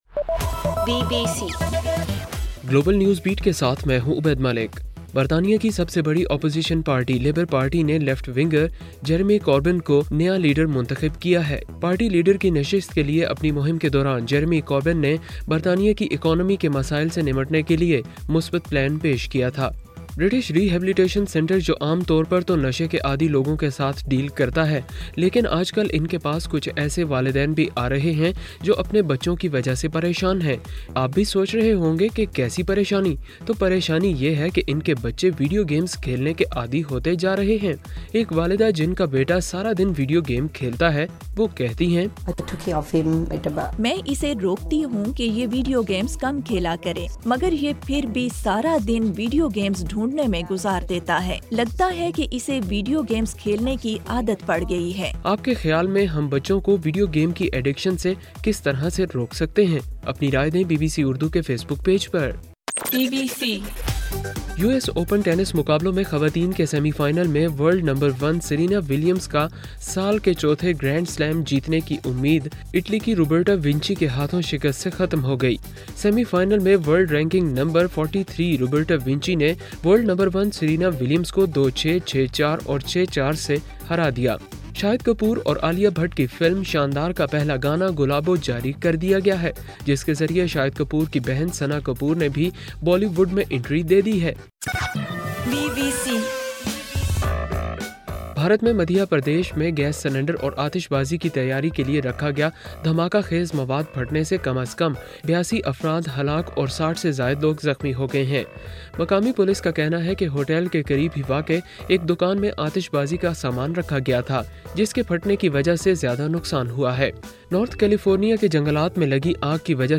ستمبر 12: رات 8 بجے کا گلوبل نیوز بیٹ بُلیٹن